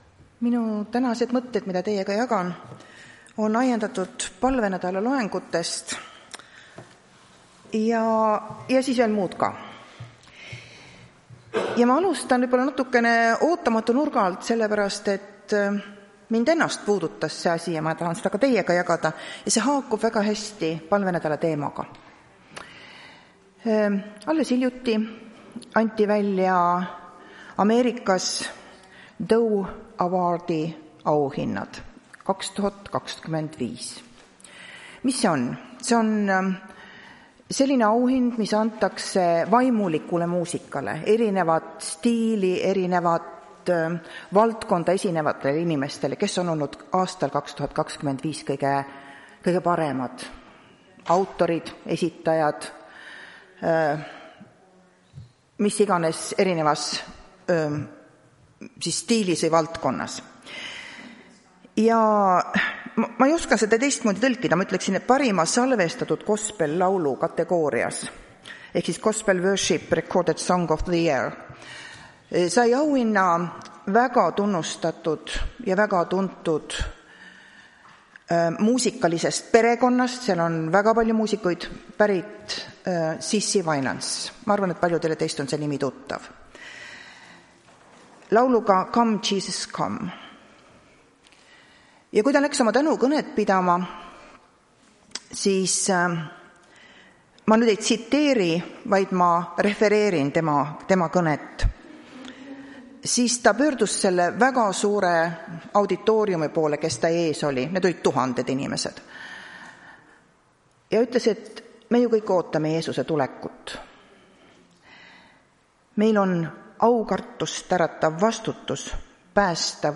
Tartu adventkoguduse 08.11.2025 teenistuse jutluse helisalvestis.